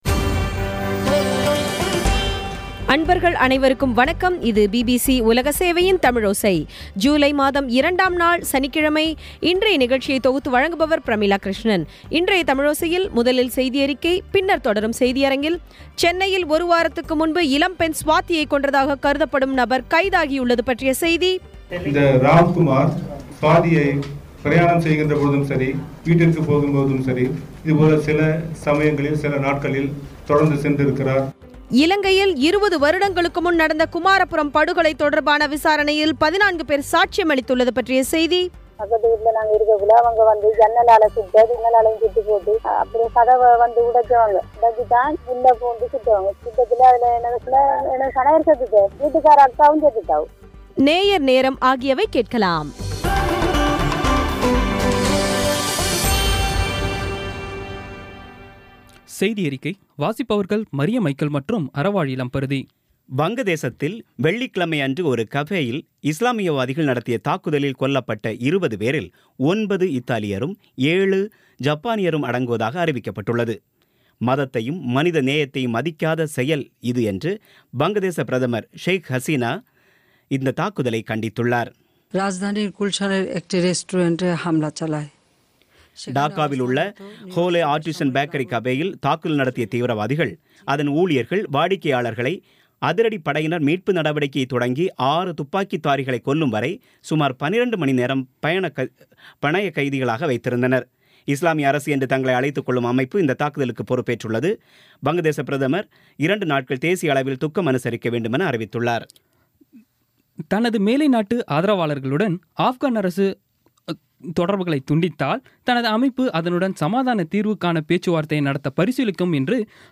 பி பி சி தமிழோசை செய்தியறிக்கை (02/07/16)